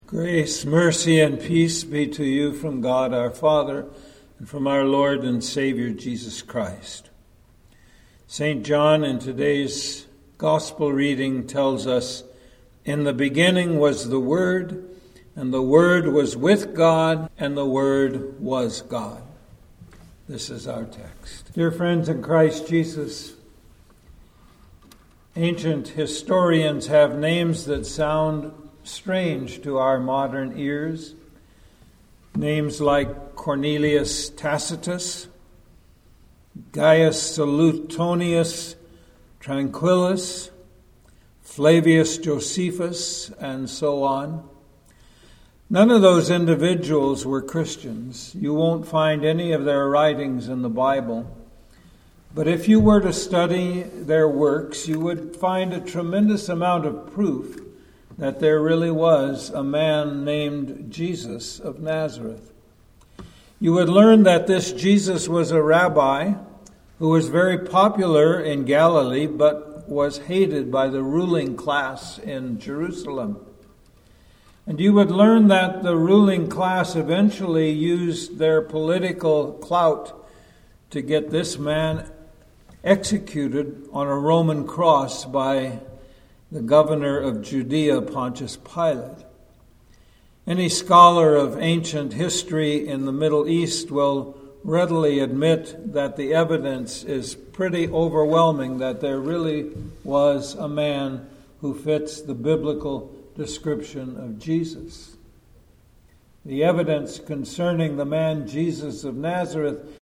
Sermon-for-Christmas-Day-2025.mp3